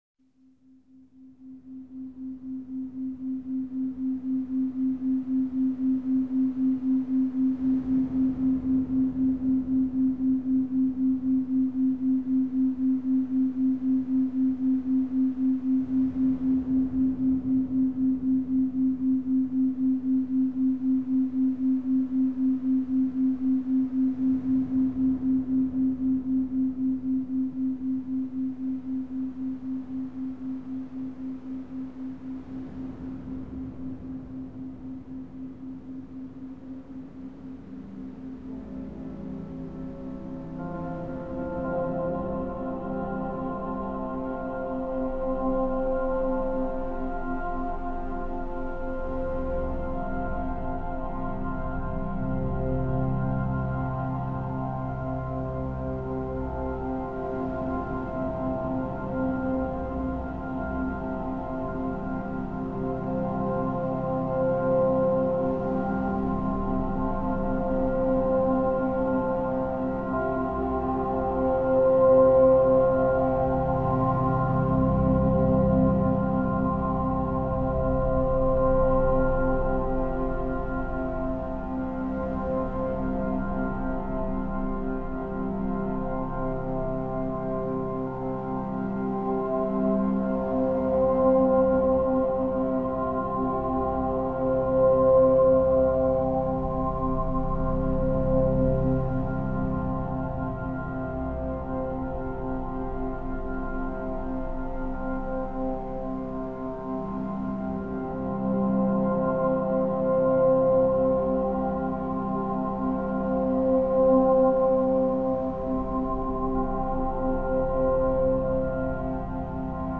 光る音楽（オーディオストロボ対応音源）
オーディオストロボ技術により製作された音源には、音にシンクロした光プログラムが埋め込まれています。